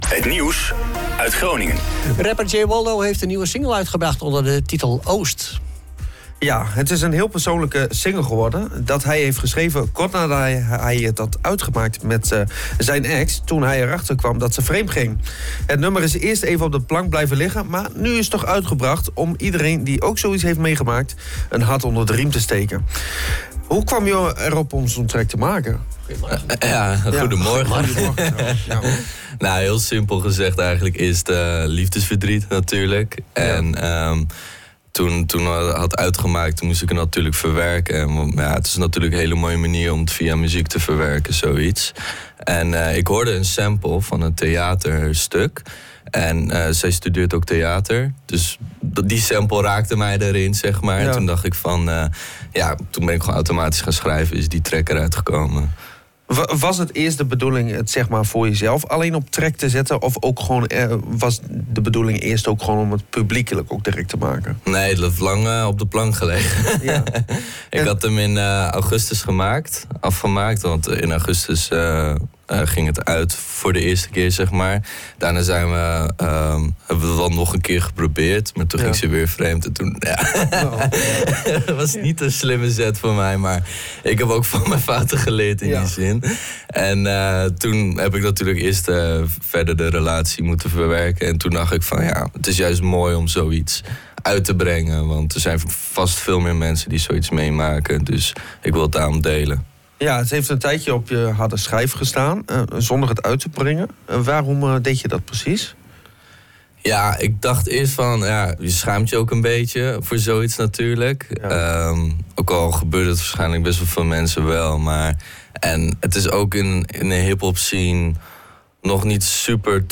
rapmuziek